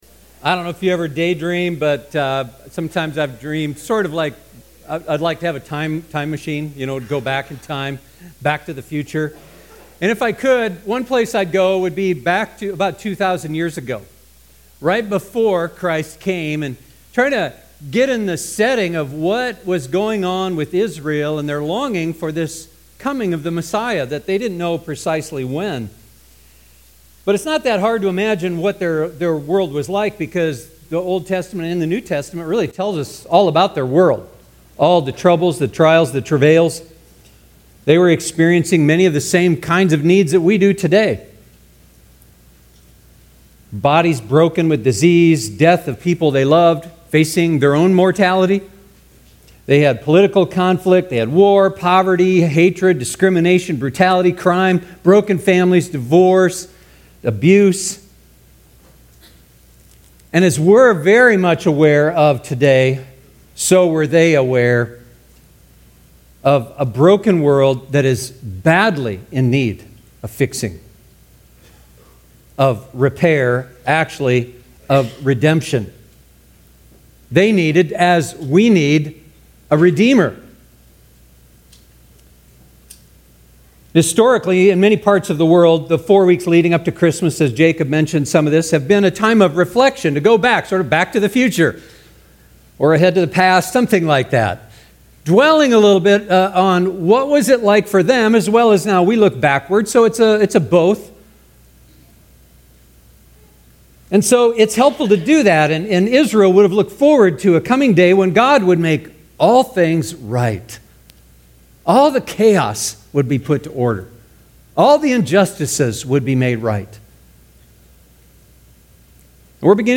Advent 2019 SERMON POWERPOINT Sunday